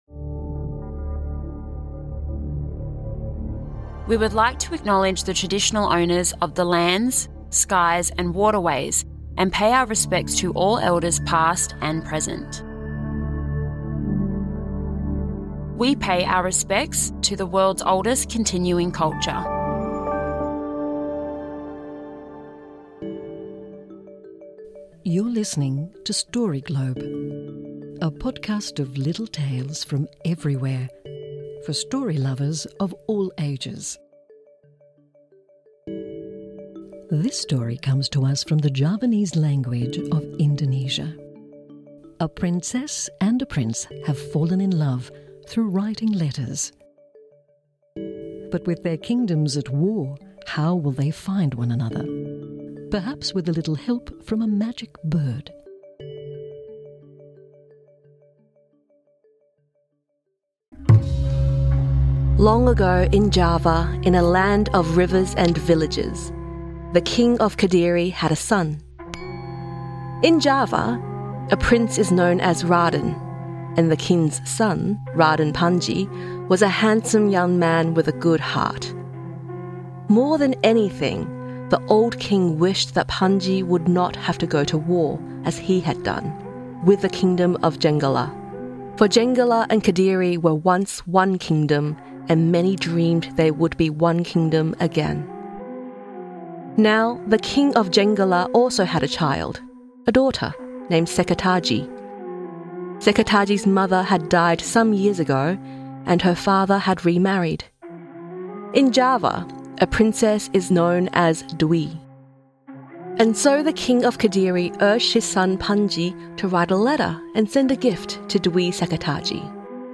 'Ande Ande Lumut: a dream come true' - a story from the Javanese language.